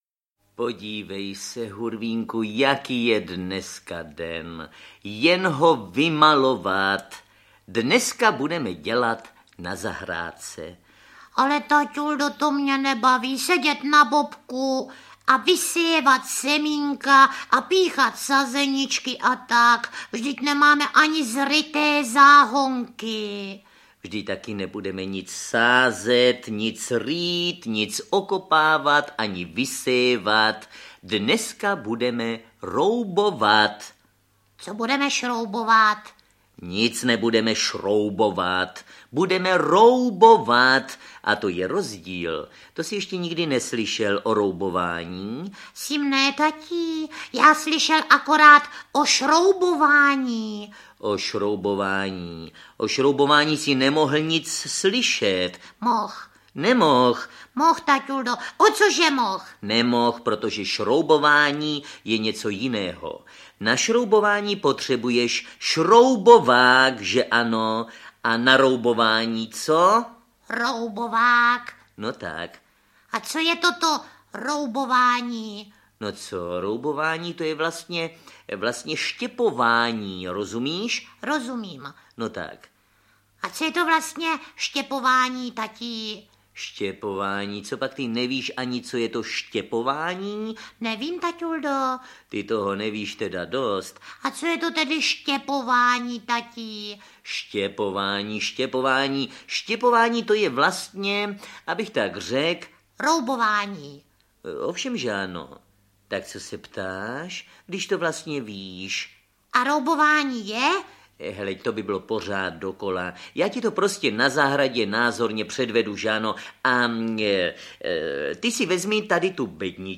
Ukázka z knihy
Další méně známé Hurvínkovy příběhy z rozhlasového archivu (ze sedmdesátých a osmdesátých let), které spojuje autorství Františka Nepila a interpretační umění Miloše Kirschnera a Heleny Štáchové